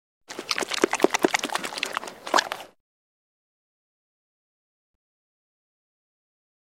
Звук бульдога, который быстро ест суп